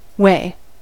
way: Wikimedia Commons US English Pronunciations
En-us-way.WAV